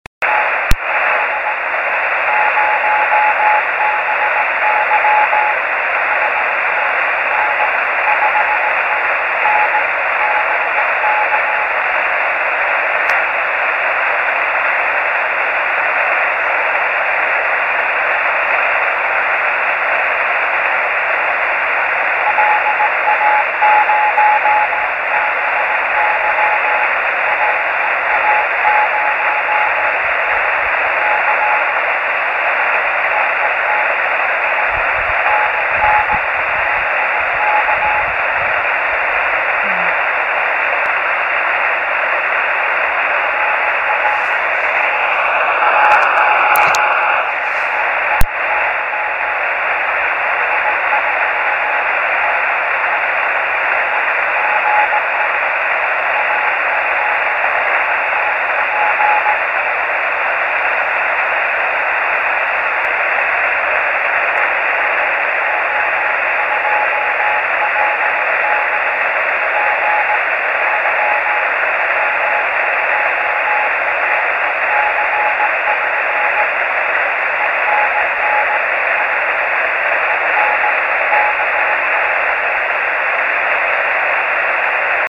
Grabaciones Rebote Lunar de KP4AO,
Radiotelescopio del Observatorio de Arecibo en Puerto Rico
Equipo: Yaesu FT-817.
Antena: Yagi 9 elementos para 432 MHz en boom de madera de 1 m de largo.
6-cw.mp3